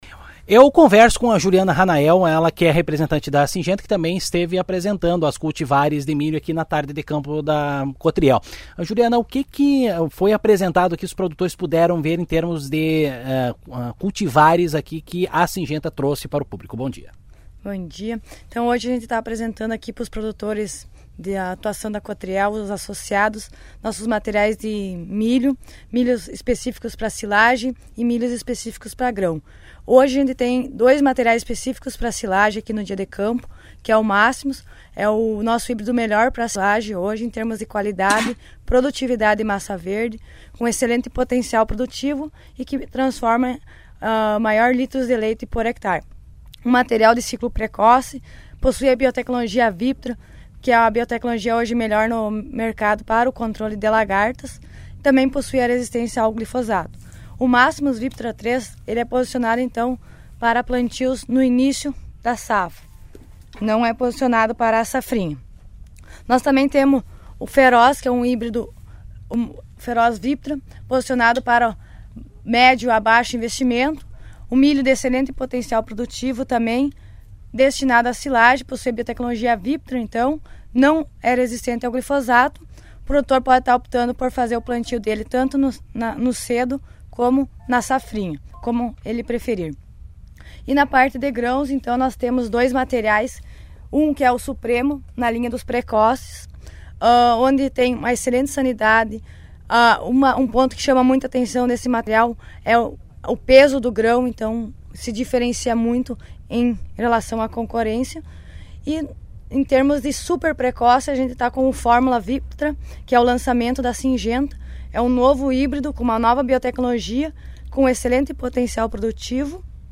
Durante a tarde de campo de milho da Cotriel na última sexta-feira, 27, a empresa Sygenta apresentou seus novos cultivares do cereal.